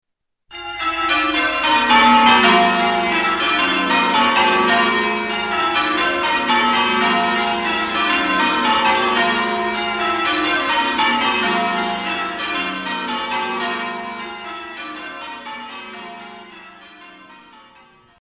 Church bell peals